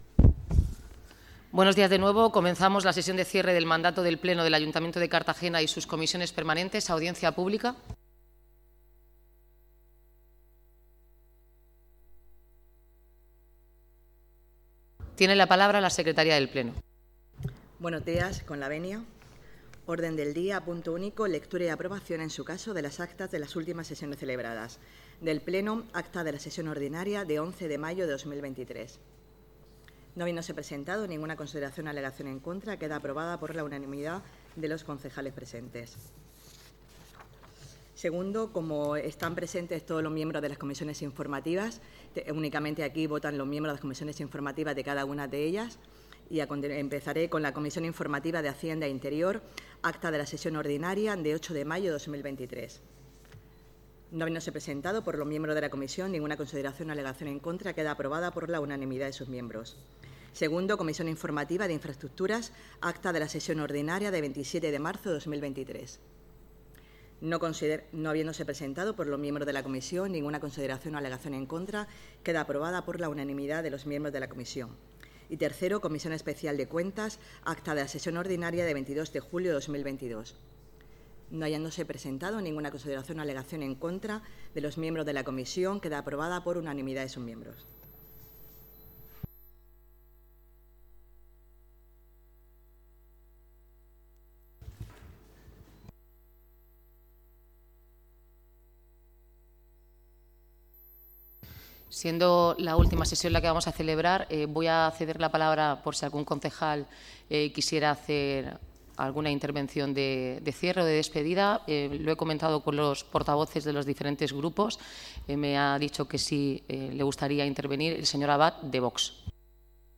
Enlace a Sesión plenaria de cierre de legislatura.
El Pleno del Ayuntamiento de Cartagena ha celebrado su sesión de cierre de mandato de esta legislatura 2019-2023 este miércoles, 14 de junio, en el Palacio Consistorial bajo la presidencia de la alcaldesa en funciones, Noelia Arroyo.